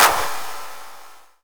Clap 4.wav